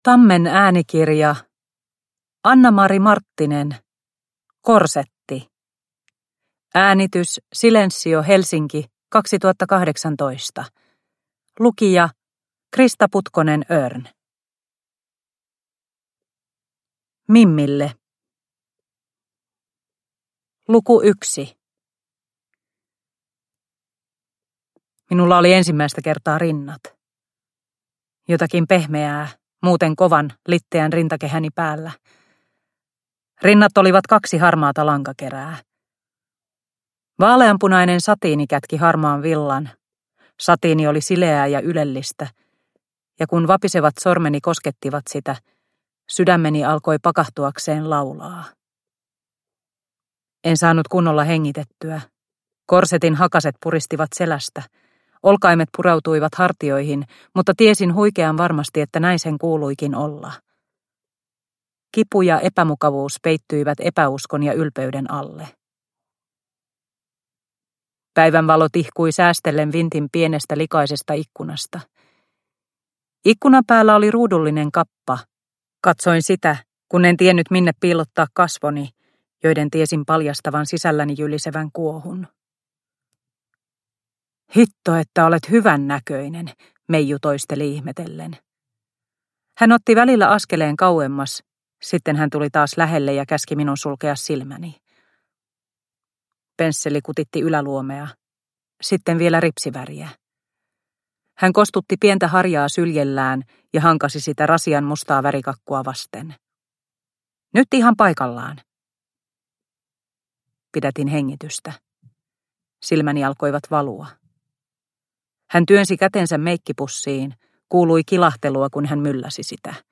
Korsetti (ljudbok) av Annamari Marttinen